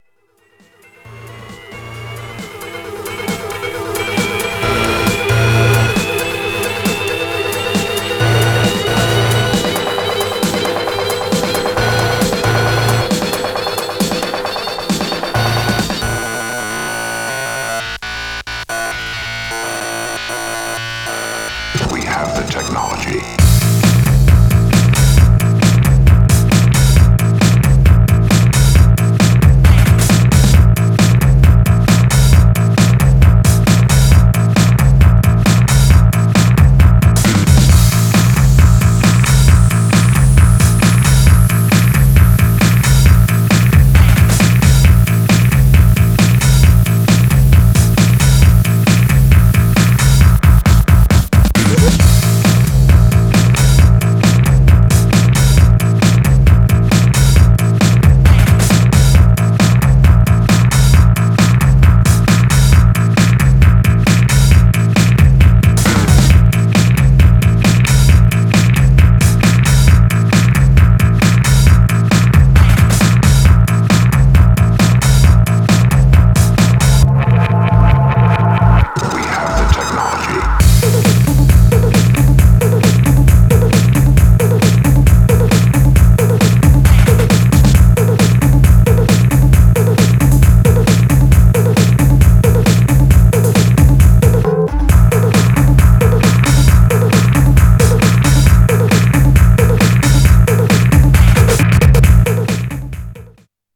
Styl: Electro, House, Breaks/Breakbeat